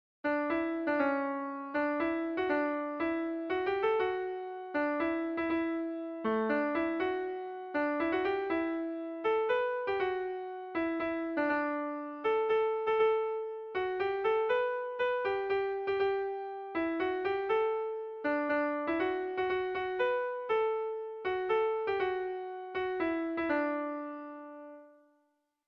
Sentimenduzkoa
ABDE